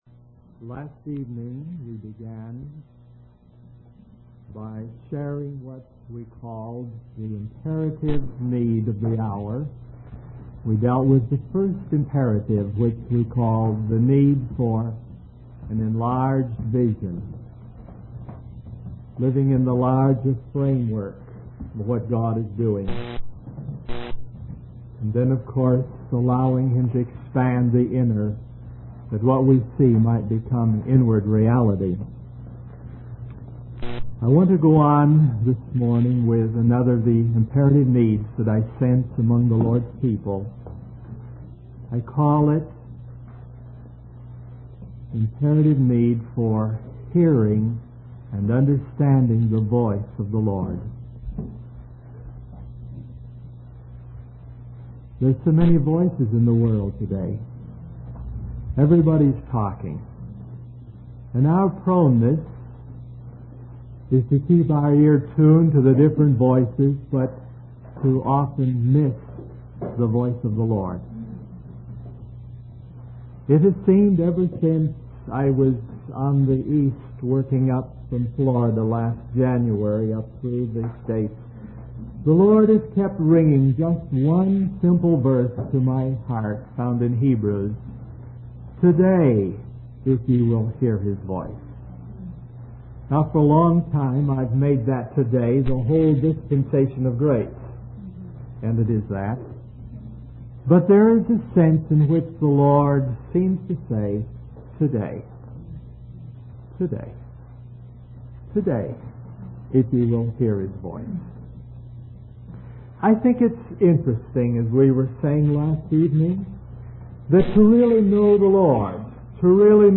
In this sermon, the speaker discusses the tendency of believers to live as though God does not exist. He compares this behavior to the prodigal son who squandered his inheritance and went his own way.